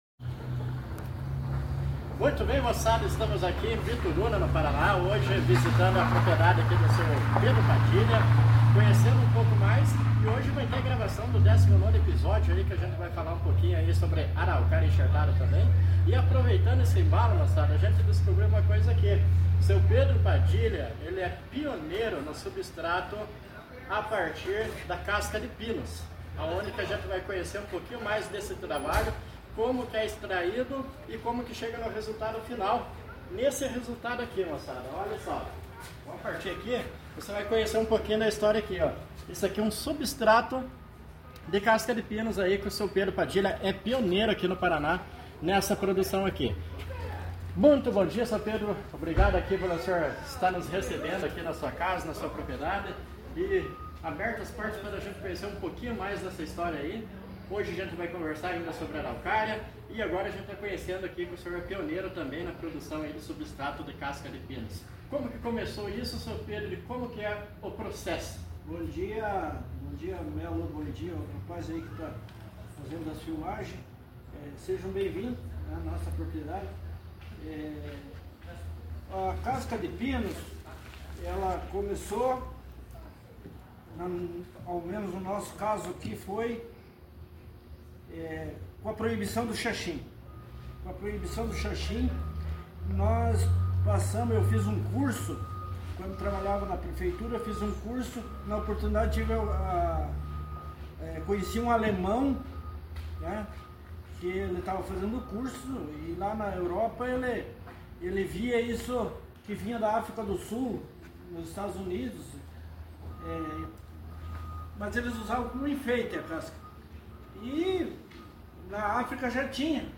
audio-entrevista-para-site.mp3